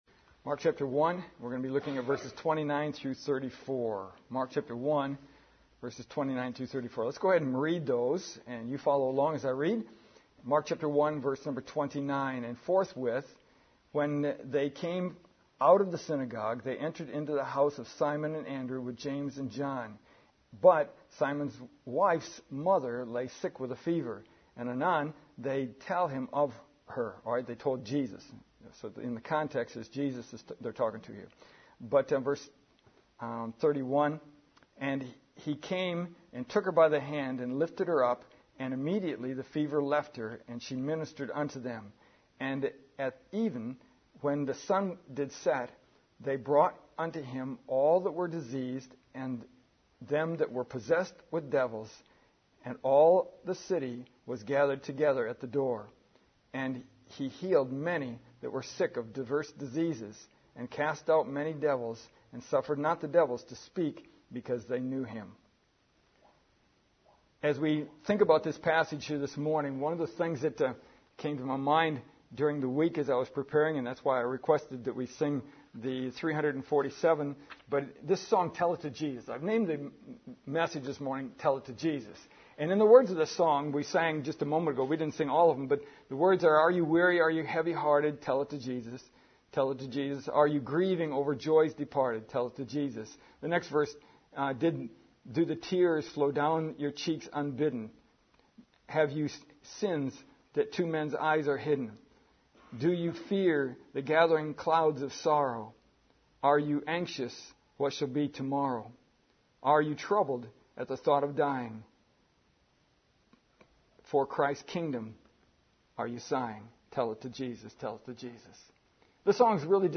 The words of the hymn “Tell it to Jesus” (Great Hymns 347) fit well with our scripture this morning.